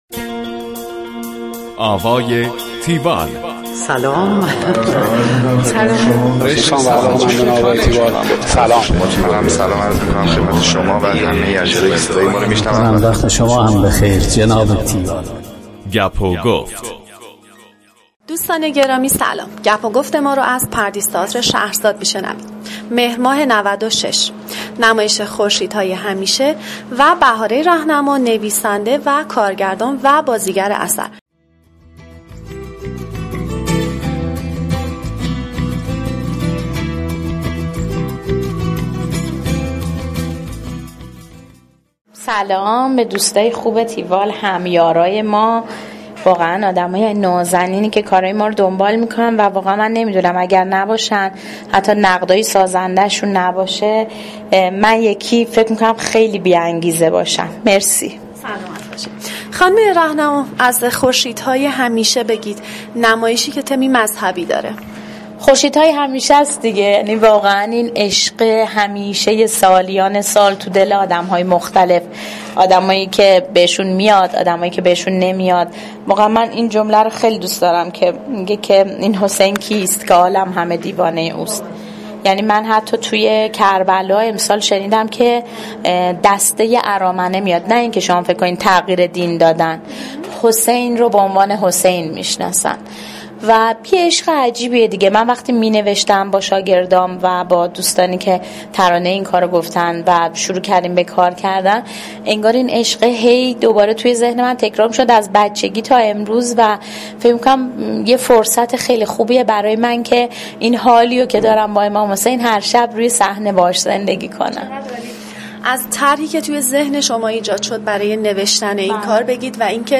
گفتگوی تیوال با بهاره رهنما